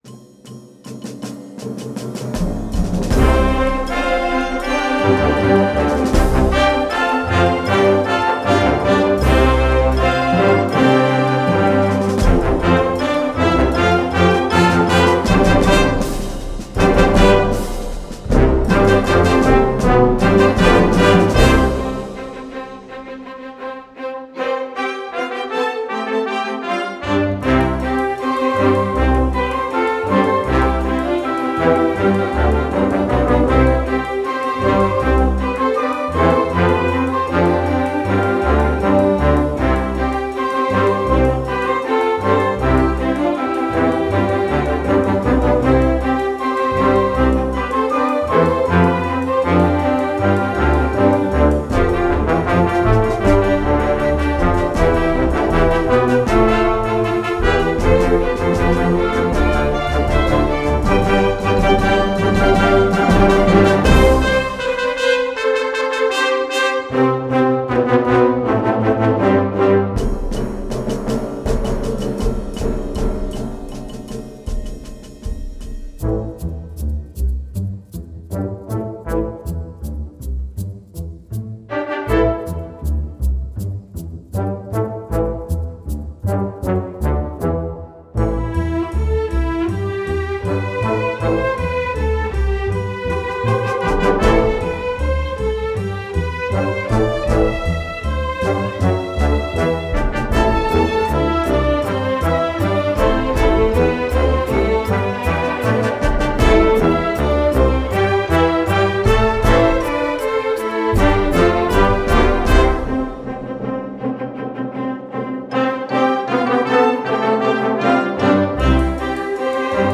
Voicing: Flex String Orchestra